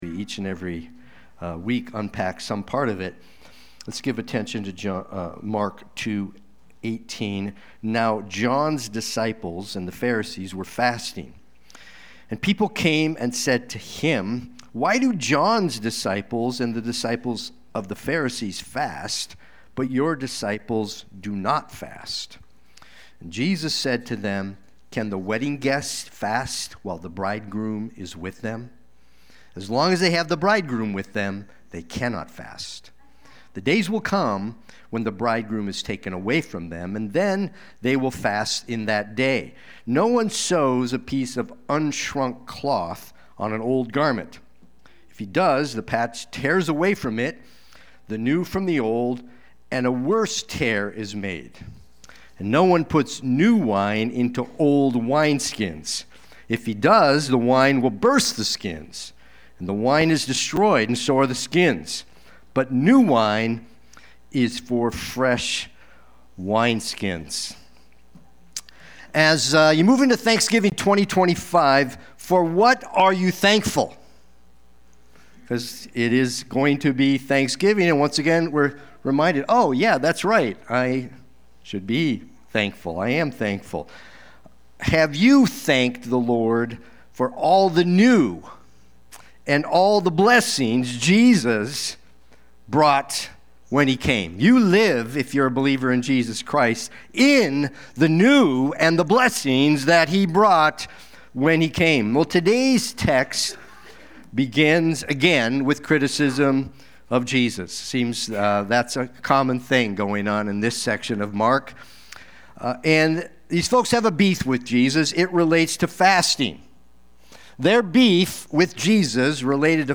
Watch the replay or listen to the sermon.